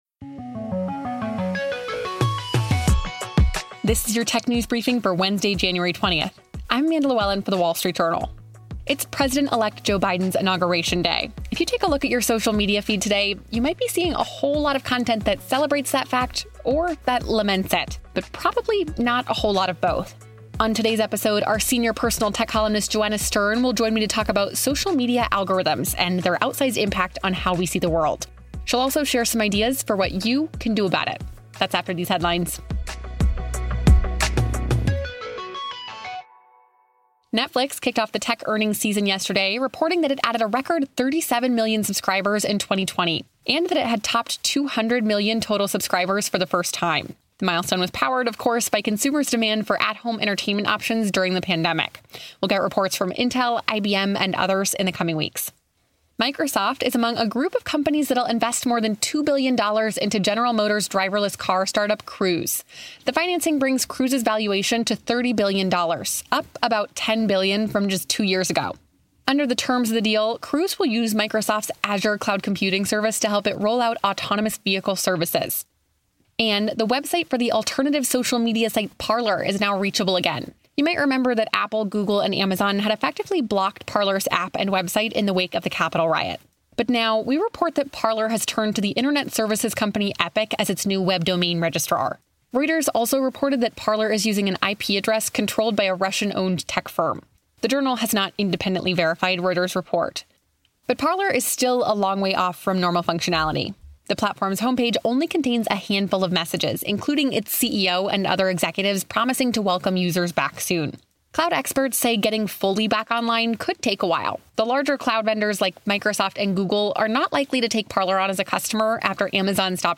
Platforms said they introduced algorithms to help us sort through the noise, but as we now know, those algorithms have had much further-reaching implications. Senior Personal Tech Columnist Joanna Stern explains how we got here and shares some ideas about potential solutions.